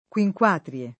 vai all'elenco alfabetico delle voci ingrandisci il carattere 100% rimpicciolisci il carattere stampa invia tramite posta elettronica codividi su Facebook Quinquatrie [ k U i j k U# tr L e ] n. pr. f. pl. stor. — antiche feste romane